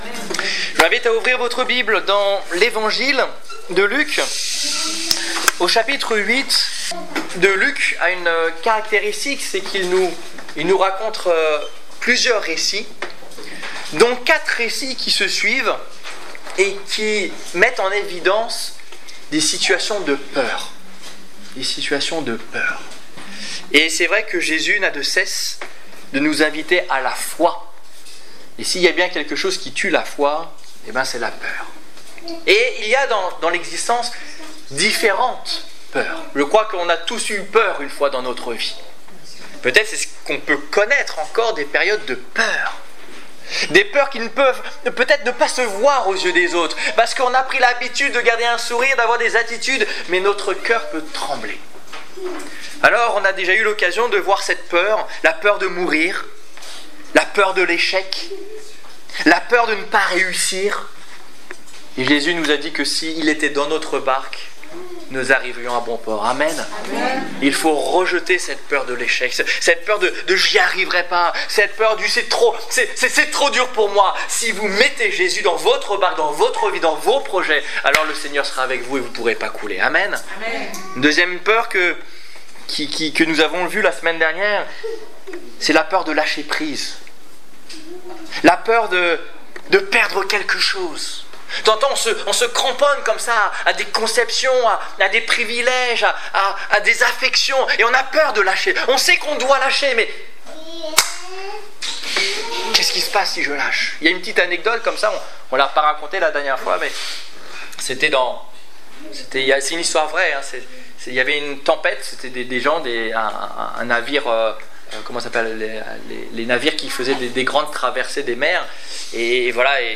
Évangélisation du 11 septembre 2015